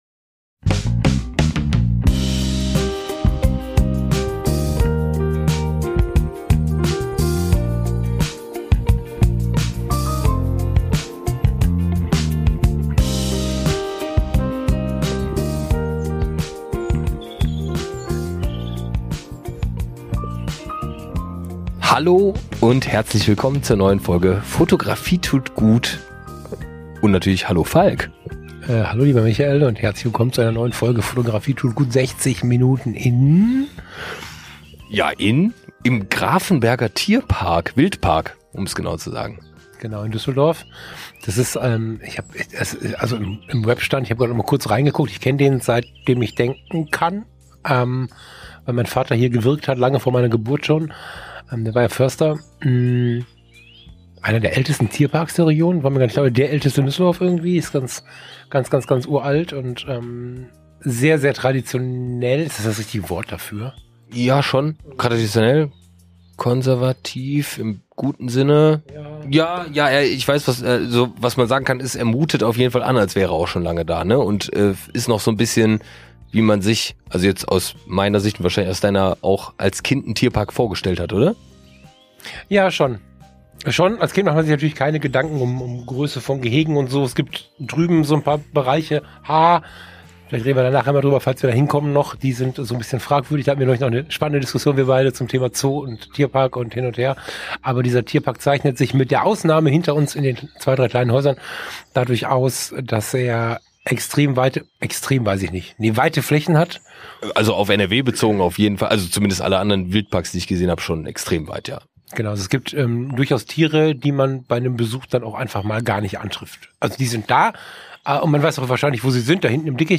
Beschreibung vor 1 Monat In unserer neuen Ausgabe von „60 Minuten in…“ zieht es uns in den Wildpark Düsseldorf im Grafenberger Wald. Da wir uns quasi auf der Mitte treffen, haben wir unser Vorgespräch direkt vor Ort unter einem Wetterschutz-Unterstand aufgenommen. Schon nach wenigen Minuten und mitten in der Aufnahme wurde uns klar: Unsere Natur-Fotografie ist nicht auf der Suche nach perfekten und spektakulären Wildlife-Aufnahmen von seltenen Situationen und noch selteneren Tieren.